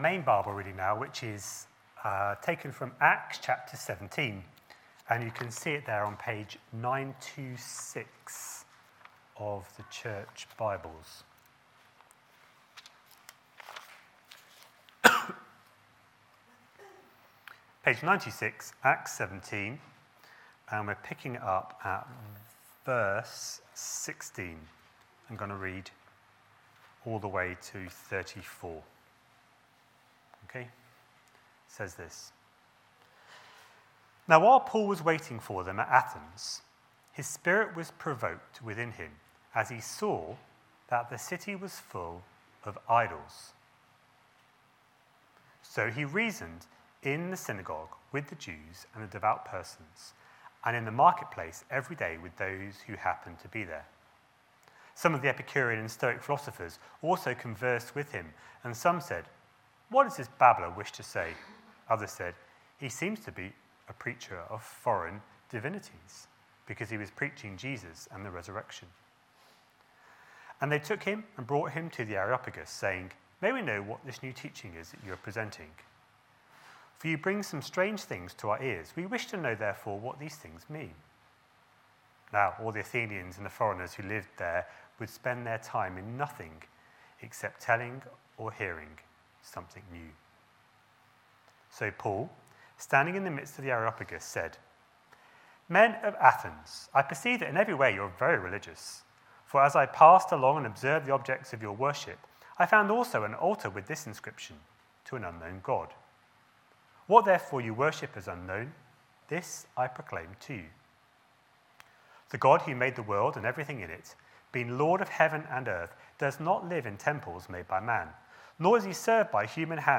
A sermon preached on 12th May, 2019, as part of our Repentance: turning from sin to God series.